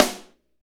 Index of /90_sSampleCDs/Northstar - Drumscapes Roland/DRM_R&B Groove/KIT_R&B Dry Kitx